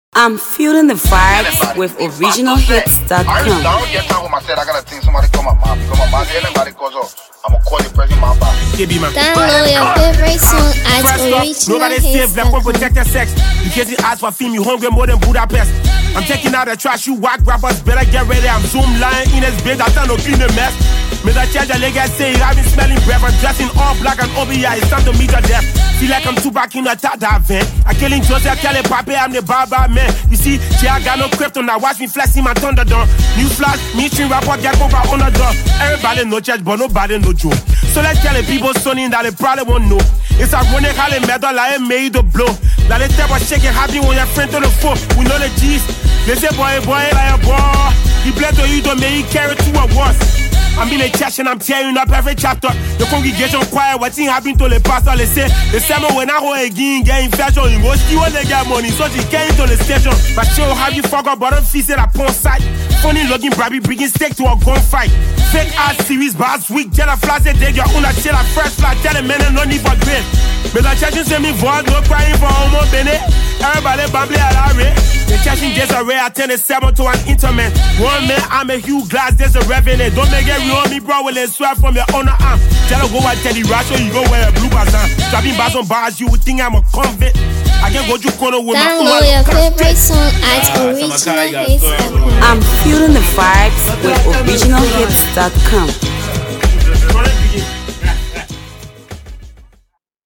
” is a full-on lyrical assault.
hardcore rap